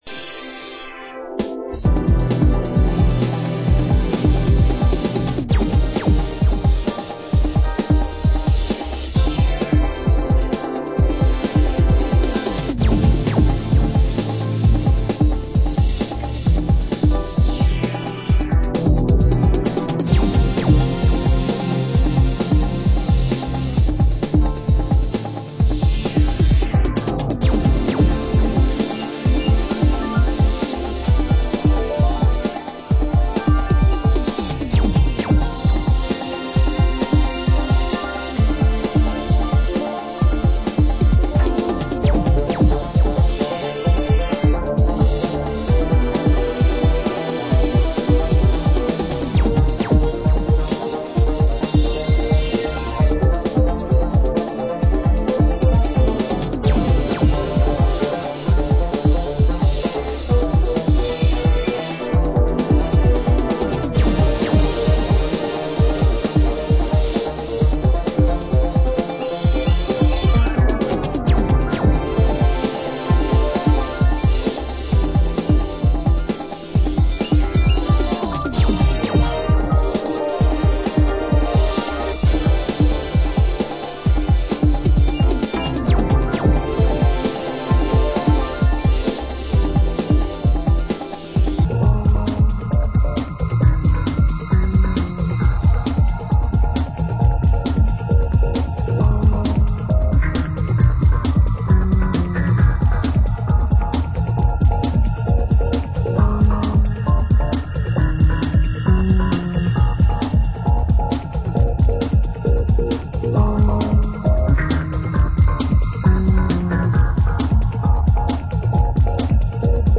Genre: Detroit Techno